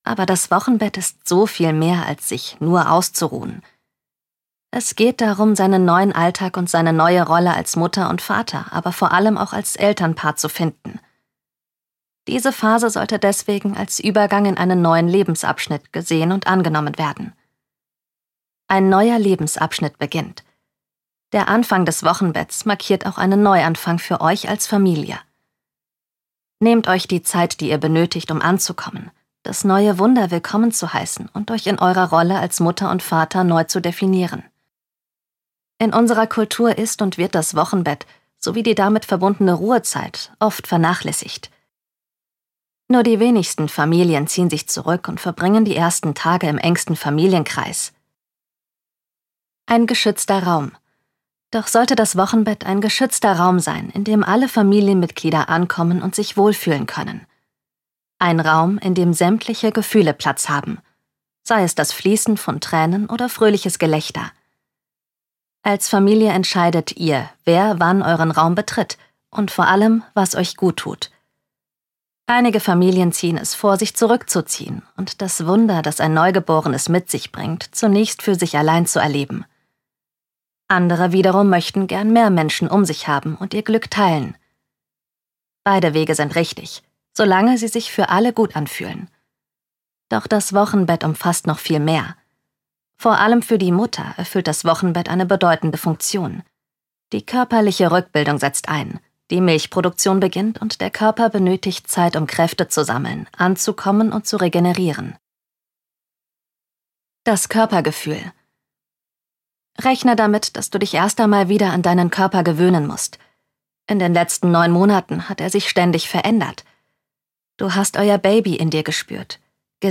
dunkel, sonor, souverän, markant, sehr variabel
Audiobook (Hörbuch), Audioguide